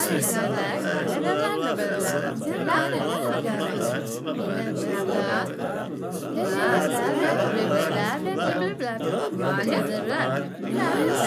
Humam Mumble [loop] (2).wav